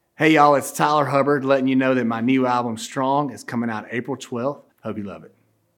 LINER Tyler Hubbard (Strong album announce)
LINER-Tyler-Hubbard-Strong-album-announce.mp3